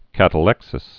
(kătl-ĕksĭs)